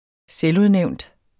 Udtale [ -uðˌnεwˀnd ]